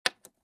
StoneSound3.mp3